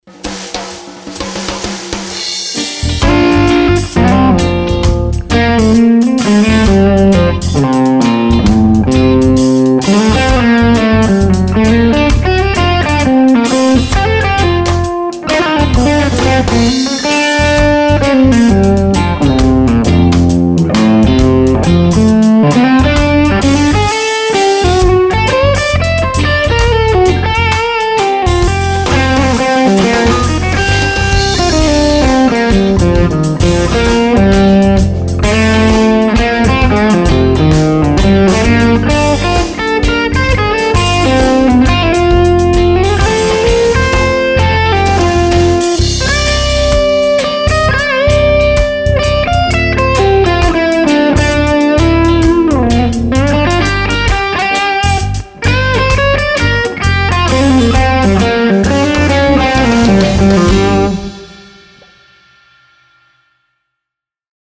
These clips are the EMU built in mic pre so no issues.
Clip from last week more gain
Duh!! I just realized I used the Audix I5 on the 185 clips....